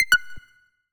Universal UI SFX / Basic Menu Navigation
Menu_Navigation01_Error.wav